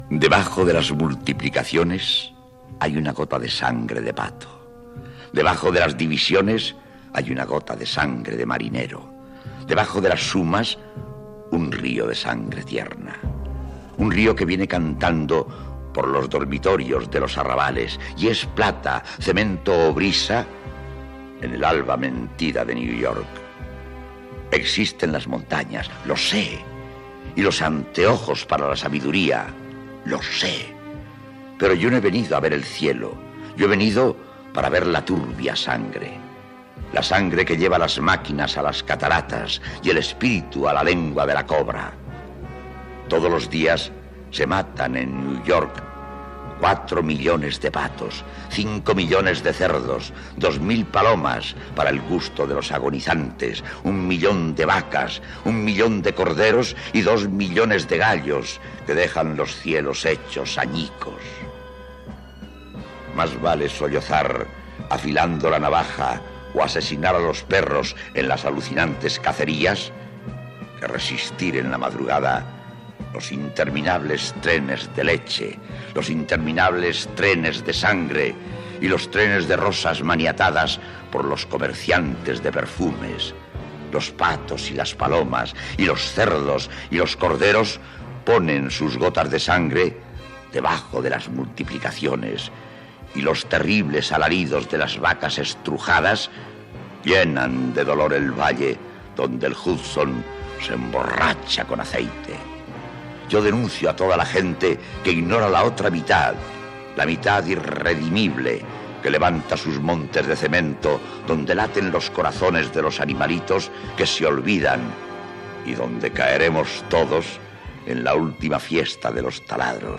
Recitat del poema "Nueva York (Oficina y denuncia" de Federico García Lorca